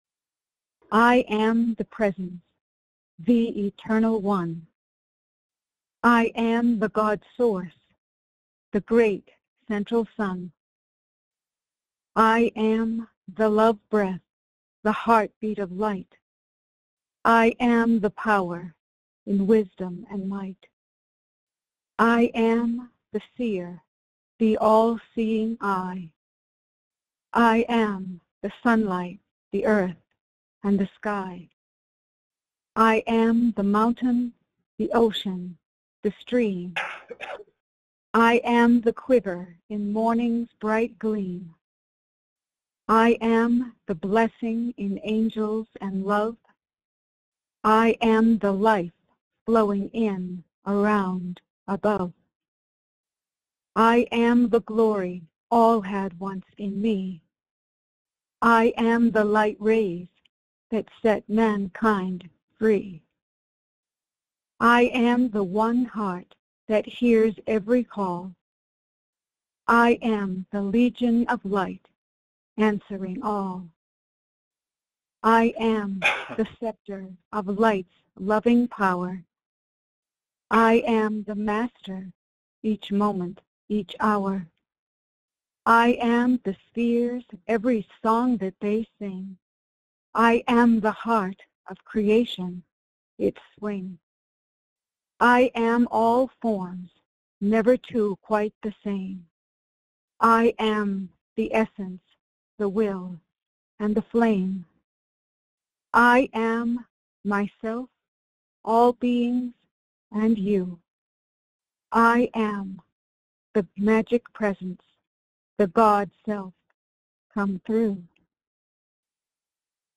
Join in group meditation with Lord Sananda.